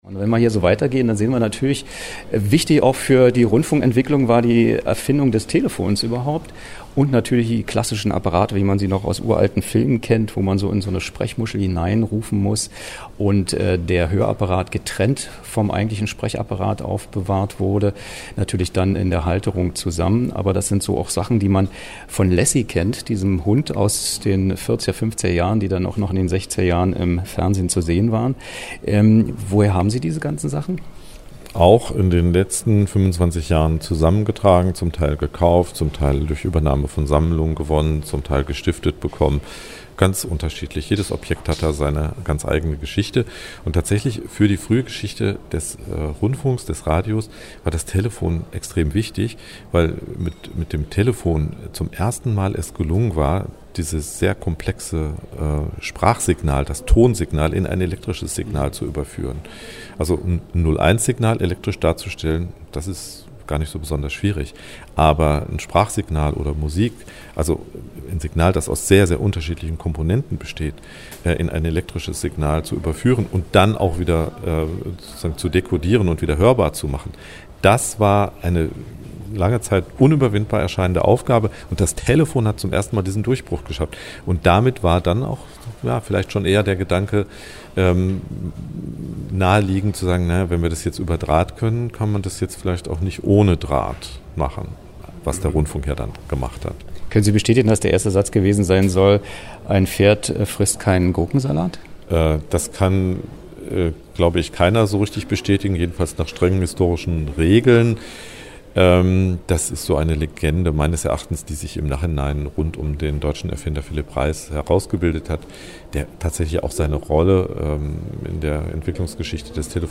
Was: Rundgang durch die Abteilung Nachrichtentechnik